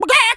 Animal_Impersonations
chicken_cluck_scream_05.wav